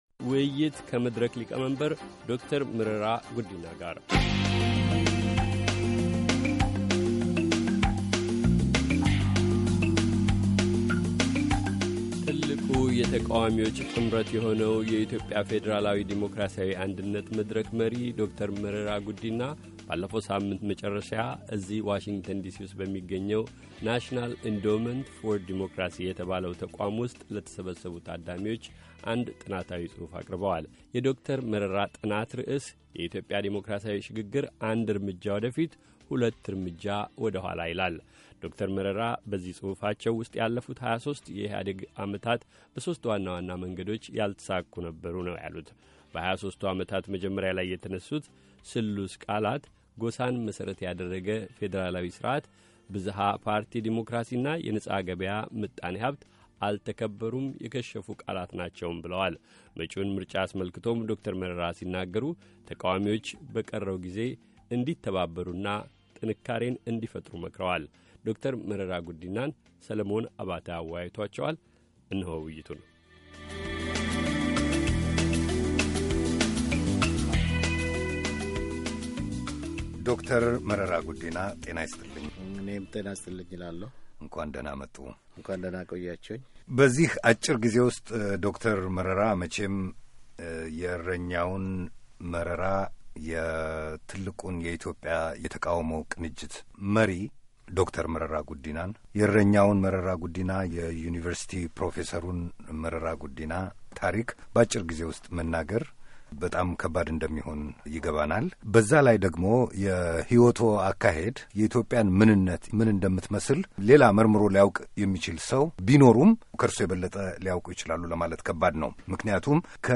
ከመድረክ መሪ ዶ/ር መረራ ጉዲና ጋር የተደረገ ቃለ-መጠይቅ